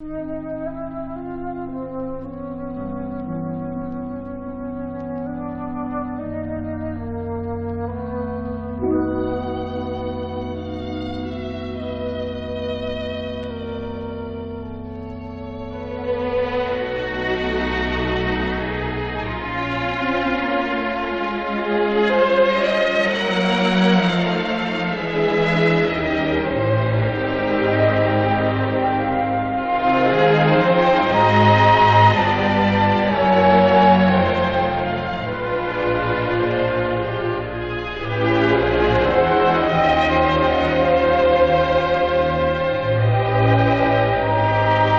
Jazz, Pop, Easy Listening　UK　12inchレコード　33rpm　Stereo